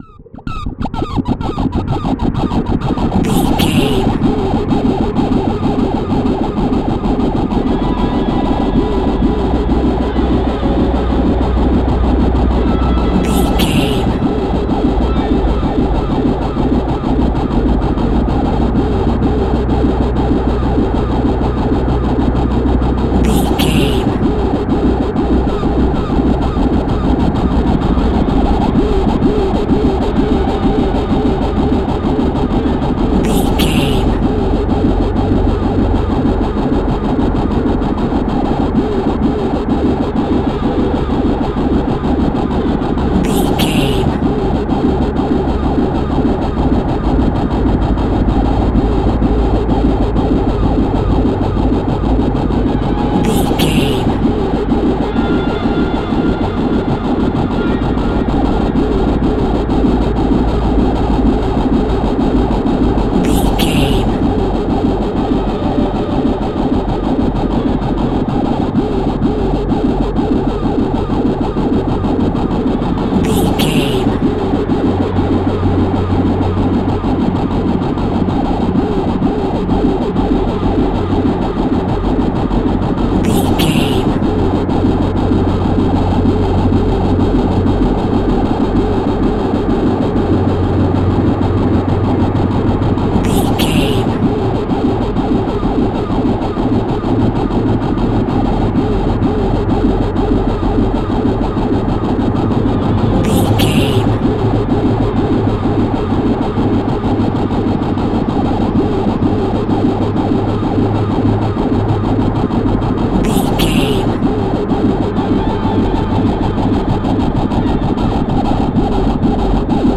Down and Deep Horror Music.
Aeolian/Minor
tension
ominous
dark
eerie
strings
synth
ambience
pads